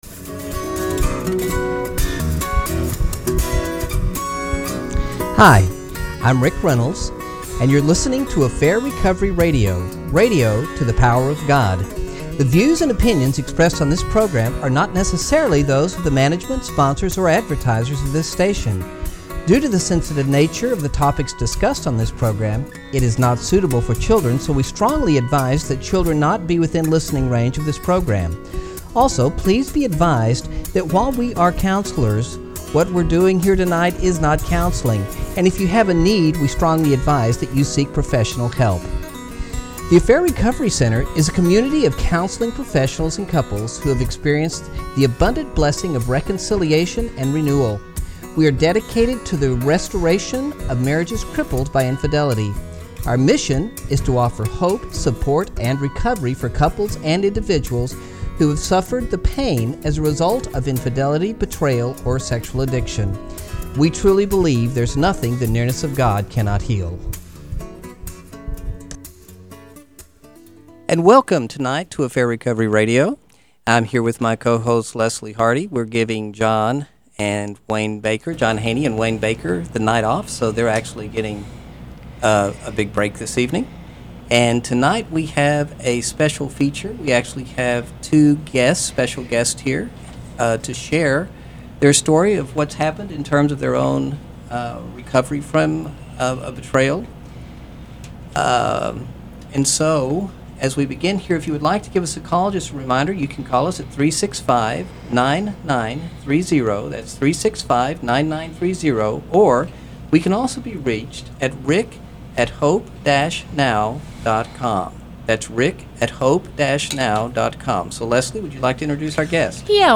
Been There, Done That: An Interview with Two Hurt Spouses | Affair Recovery